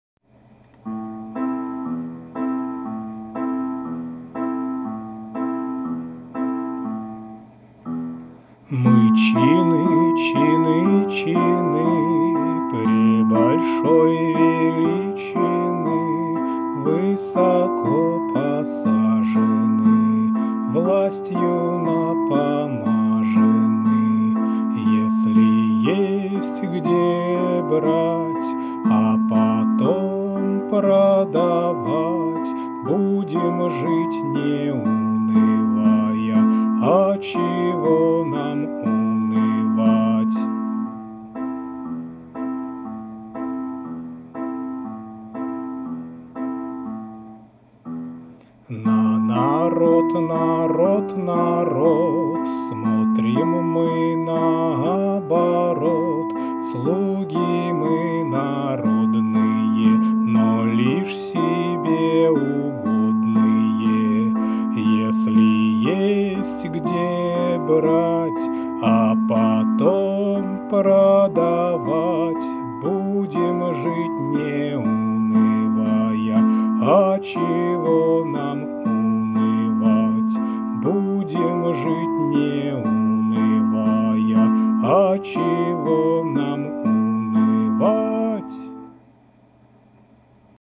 • Жанр: Детская